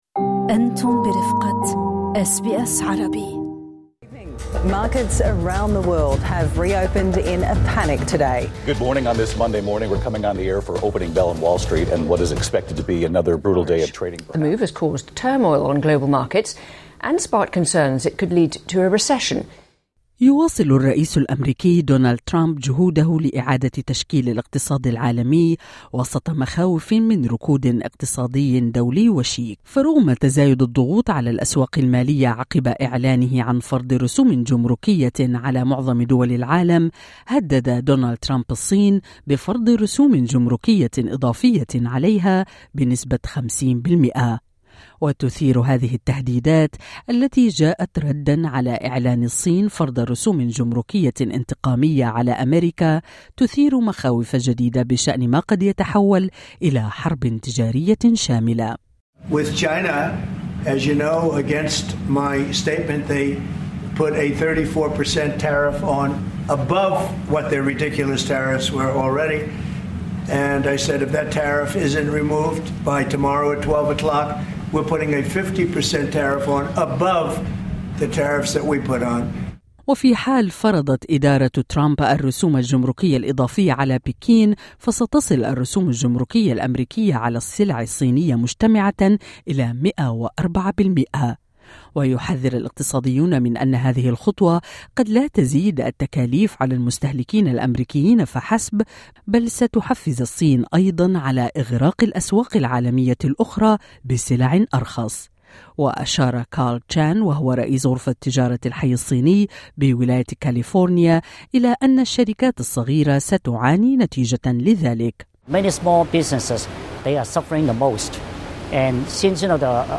كيف ستمنع ضرائب ترامب خفض سعر الفائدة في استراليا؟ خبير اقتصادي يجيب